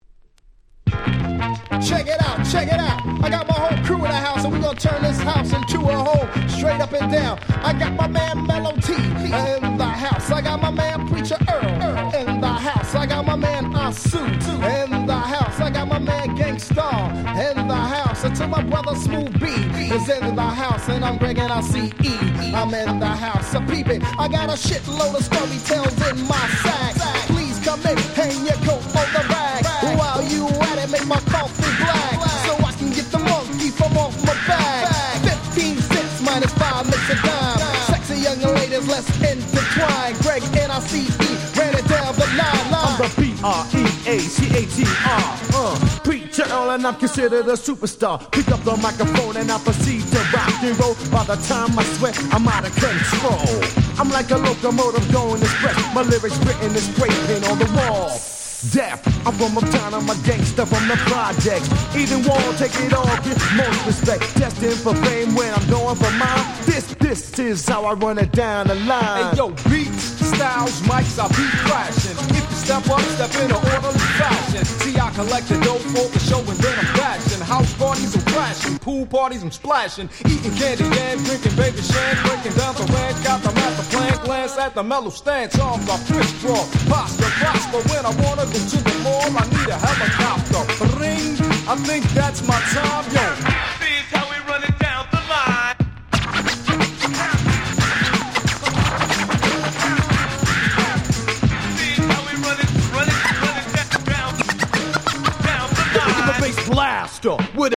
ナイスアンドスムース 90's Boom Bap ブーンバップ